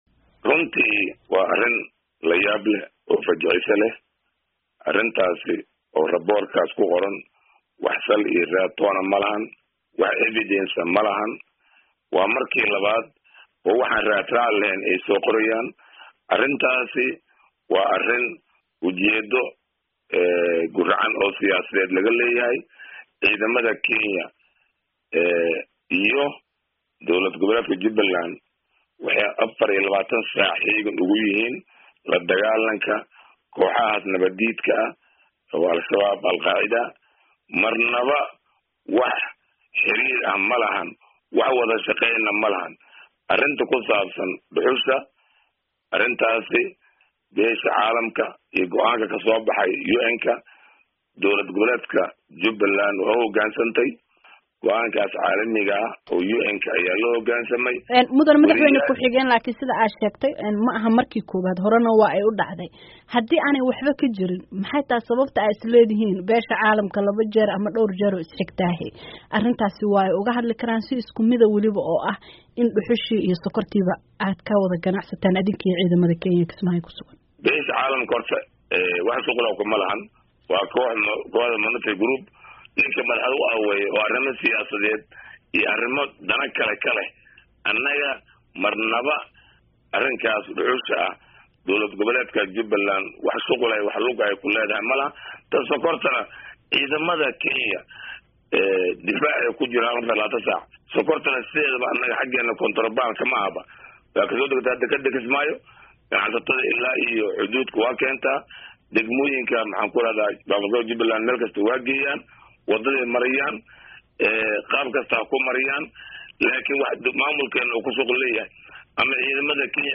Cabdulqaadir Xaaji Maxamed Lugadheere oo wareysi siiyey VOA-da